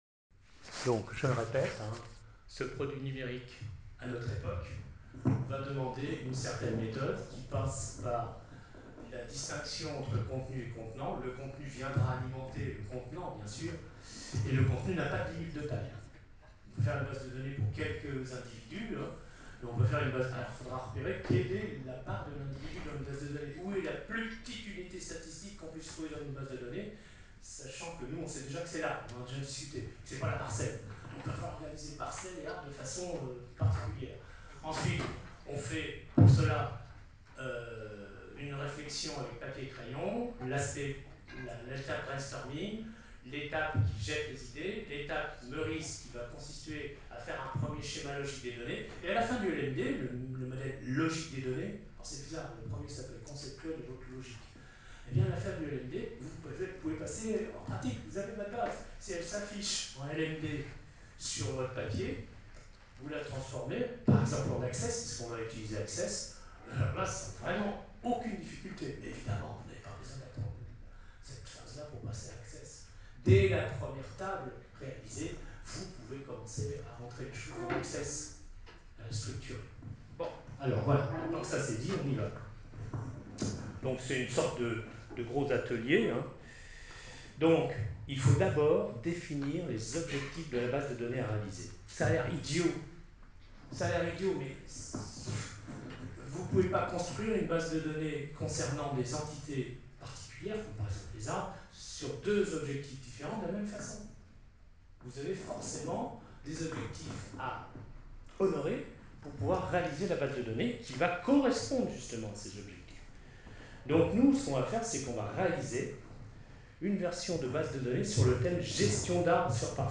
Enregistrement audio (live) Enregistrement audio monté du cours du 15 fev 2024